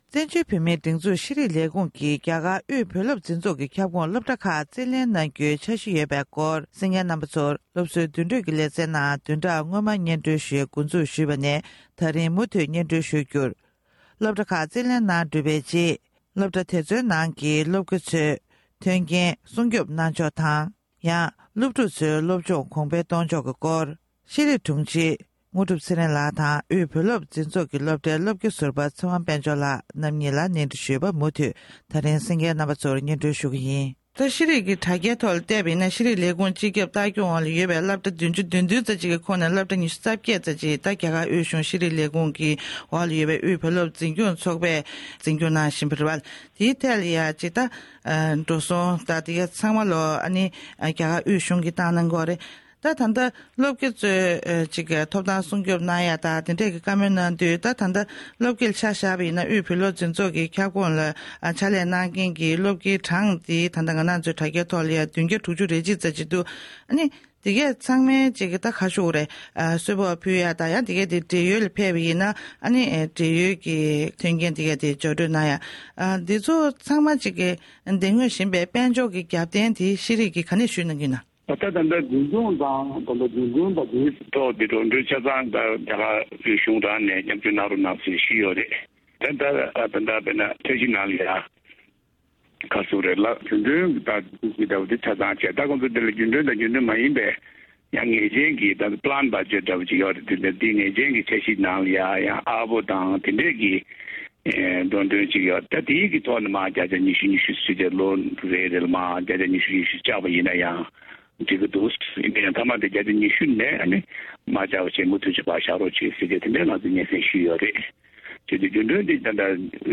བཙན་བྱོལ་བོད་མིའི་སྒྲིག་འཛུགས་ཀྱི་ཤེས་རིག་ལས་ཁུངས་ནས་རྒྱ་གར་དབུས་བོད་སློབ་འཛིན་ཚོགས་ཁྱབ་ཁོངས་སློབ་གྲྭ་ཁག་རྩིས་ལེན་གནང་རྒྱུའི་འཆར་གཞི་ཡོད་པ་ལྟར། དེ་འབྲེལ་གྱི་གླེང་མོལ་ཞུས་པའི་དམིགས་བསལ་ལས་རིམ།